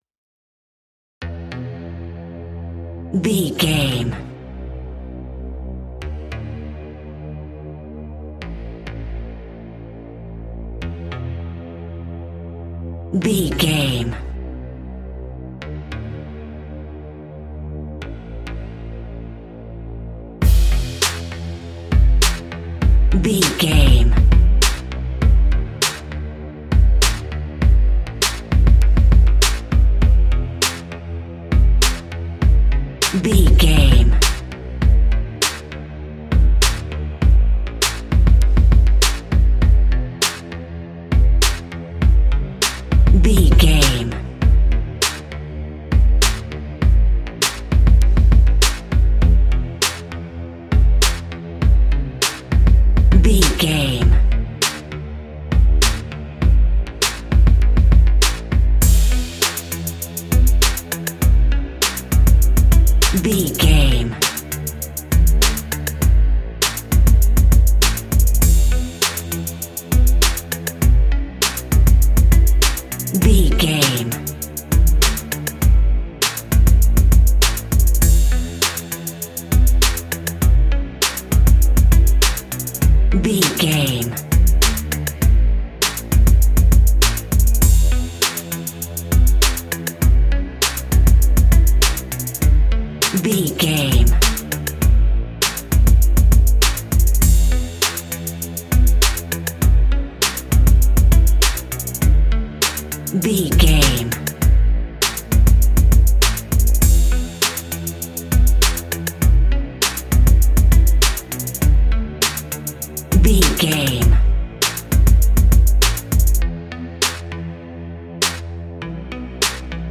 Ionian/Major
instrumentals
chilled
laid back
groove
hip hop drums
hip hop synths
piano
hip hop pads